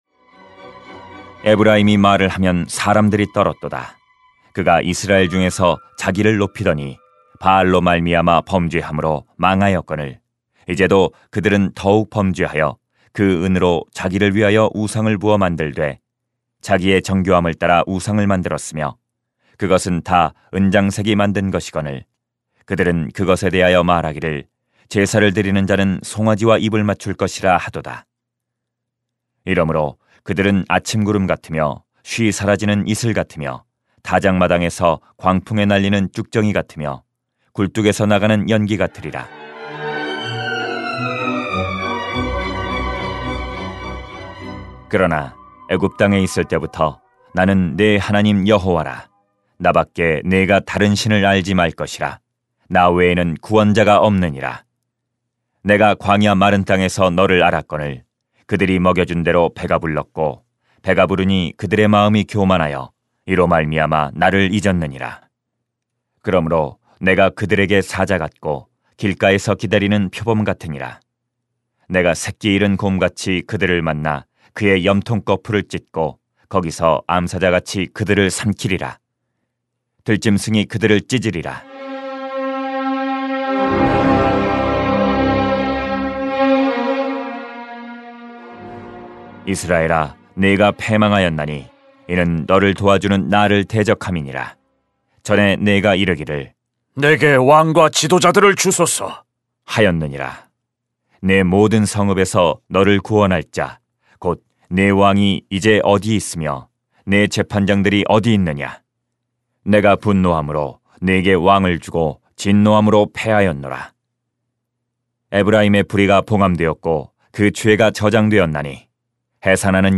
[호 13:1-16] 심판하실 하나님을 기억해야 합니다 > 새벽기도회 | 전주제자교회